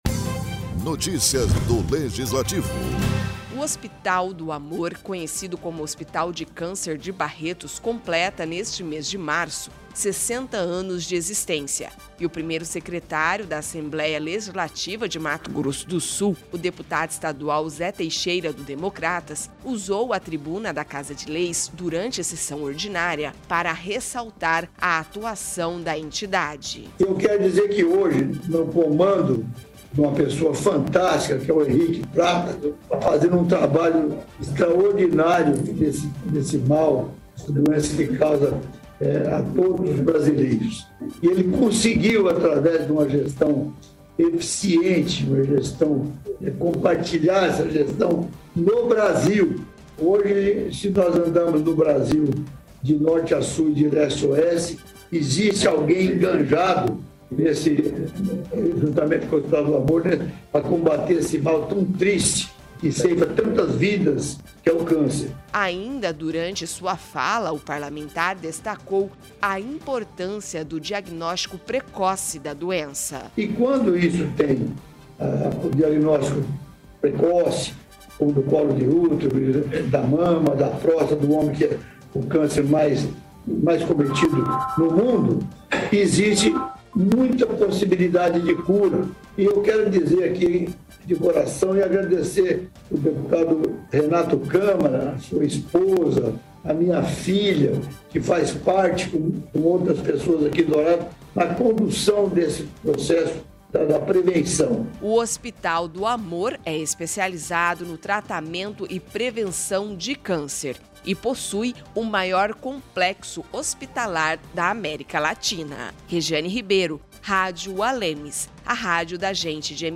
O Hospital do Amor, conhecido como Hospital de Câncer de Barretos completa neste mês março, 60 anos de existência, e o primeiro secretário da Assembleia Legislativa de Mato Grosso do Sul (ALEMS), o deputado estadual Zé Teixeira (DEM), usou a tribuna da Casa de Leis, durante sessão ordinária, para ressaltar a atuação da entidade.